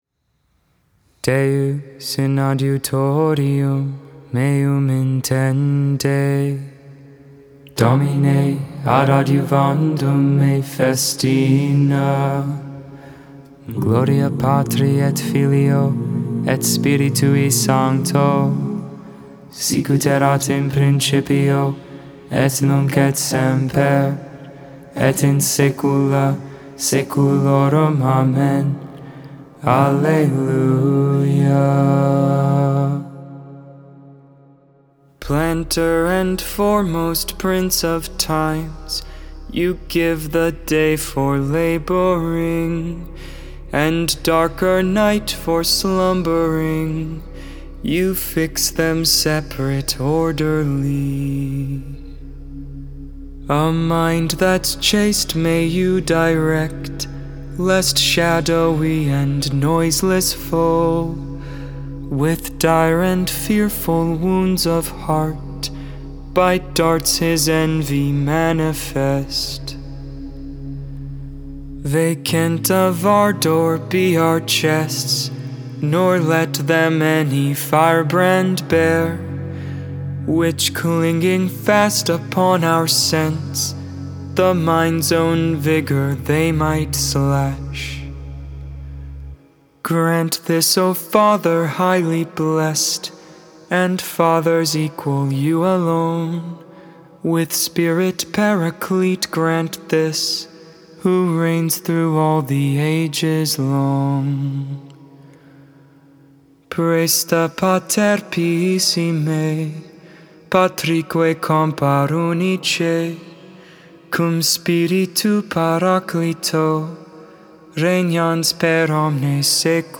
5.24.22 Vespers, Tuesday Evening Prayer